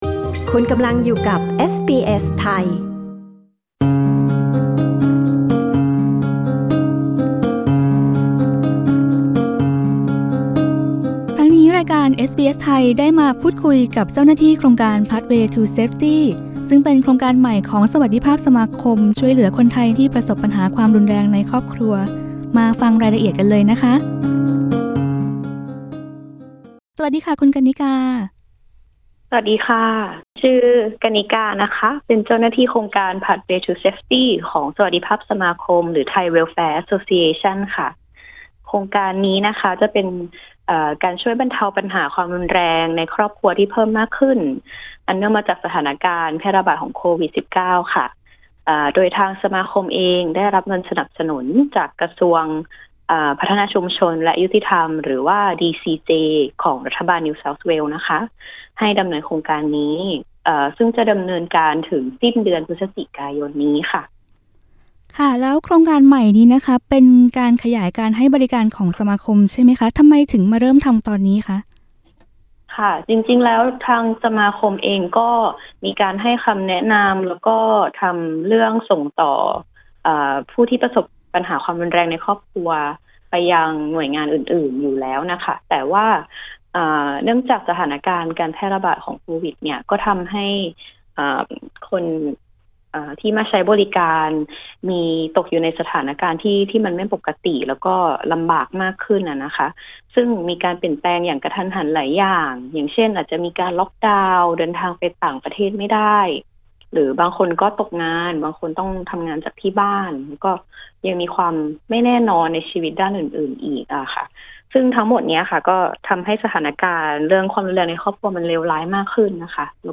เอสบีเอส ไทย พูดคุยกับเจ้าหน้าที่โครงการจากสวัสดิภาพสมาคม หรือ Thai Welfare Association ที่มาแนะนำรายละเอียดและบริการของโครงการนี้